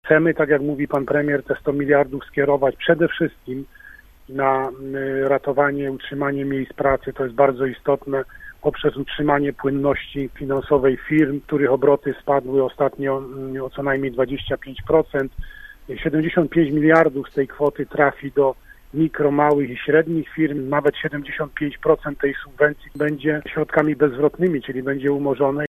Tłumaczy wojewoda lubuski, Władysław Dajczak: